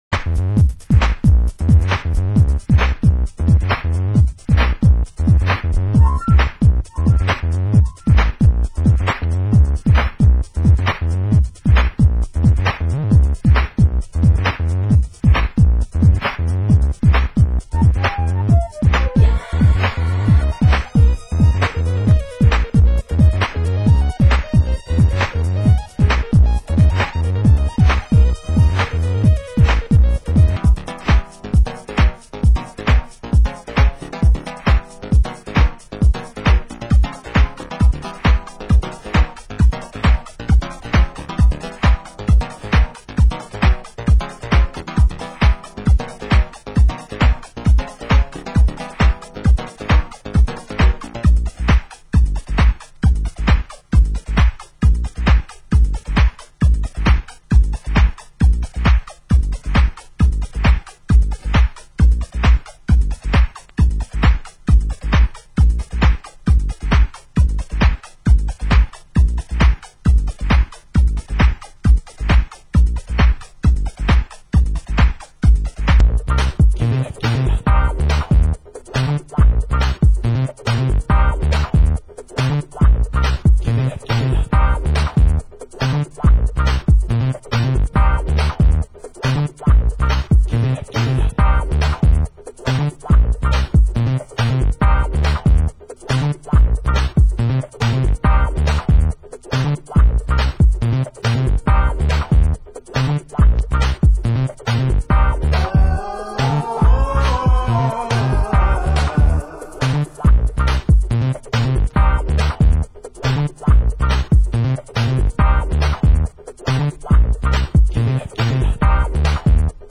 Genre: Ghetto Tech